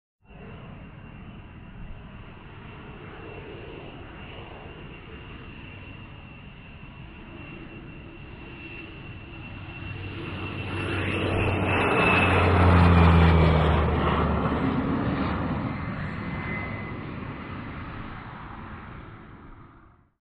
AIRCRAFT PROP TWIN TURBO: EXT: Fly by fast speed.